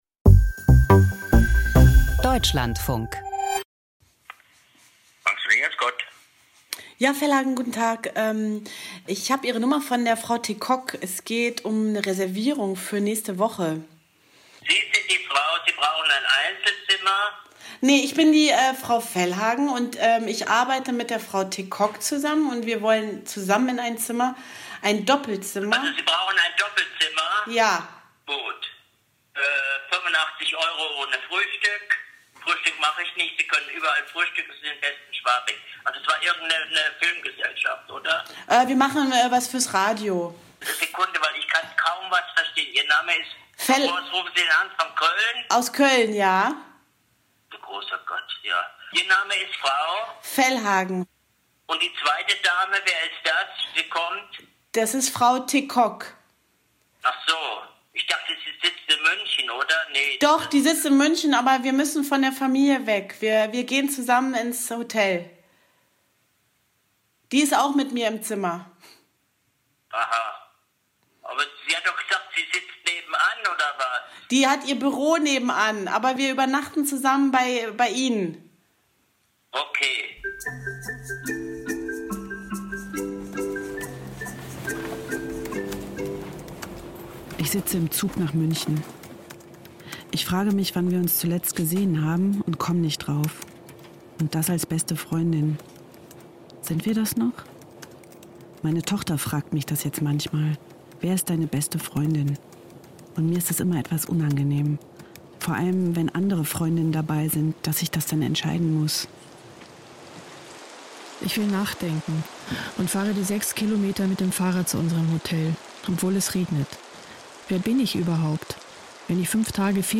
Feature